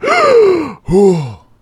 breath03.ogg